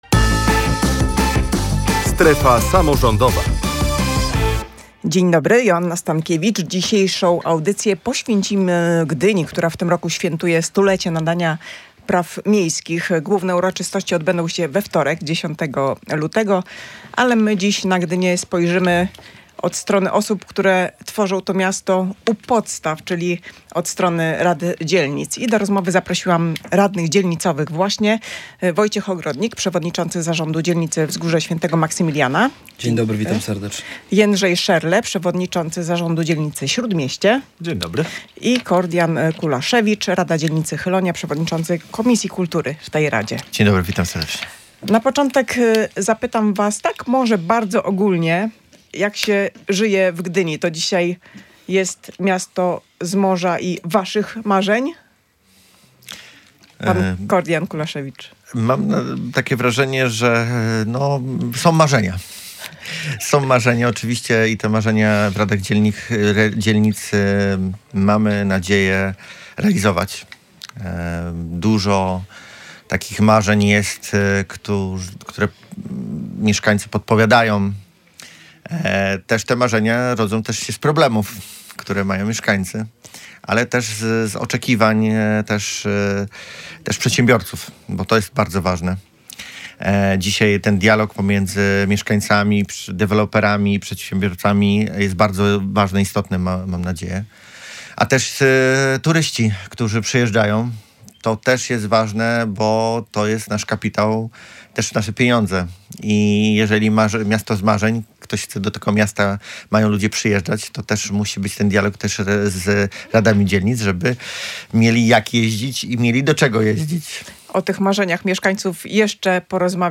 O tym, jaka powinna być przyszłość miasta, dyskutowano w audycji „Strefa Samorządowa” w Radiu Gdańsk.